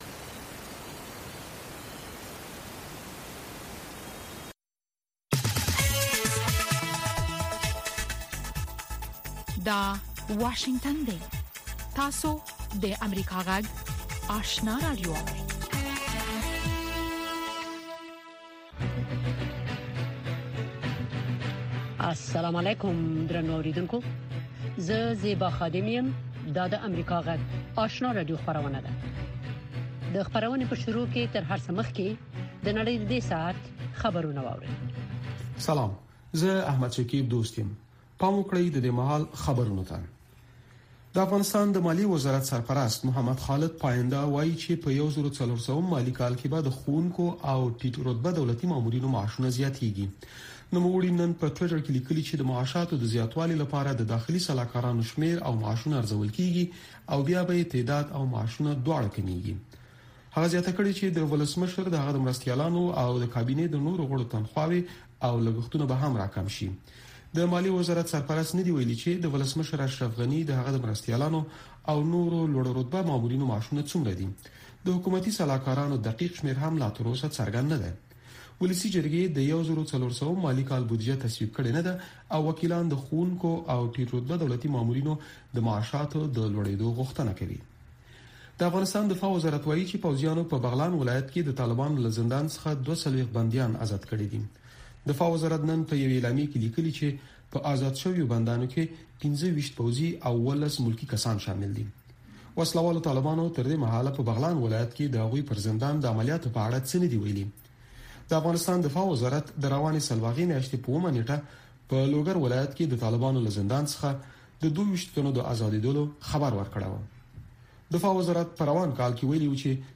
لومړنۍ ماښامنۍ خبري خپرونه
په دې نیم ساعته خپرونه کې د افغانستان او نړۍ تازه خبرونه، مهم رپوټونه، مطبوعاتو ته کتنه او مرکې شاملې دي.